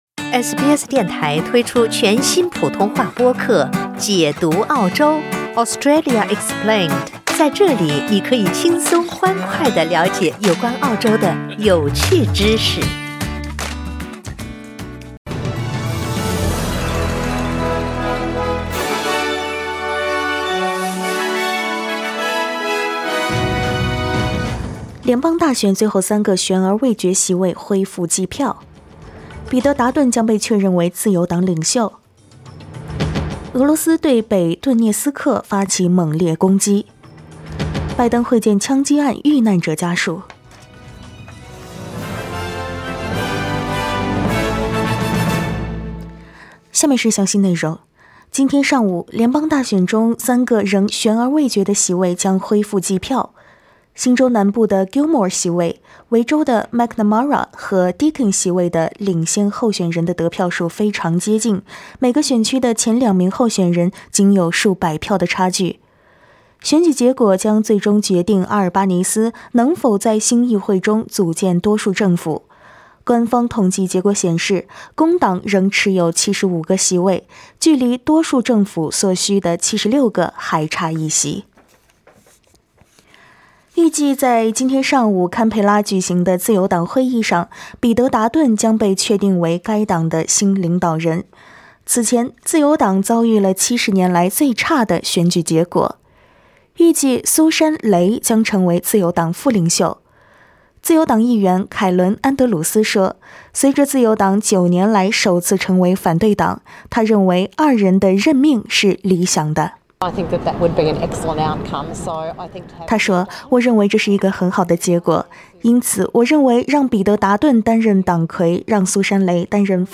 SBS早新闻（5月30日）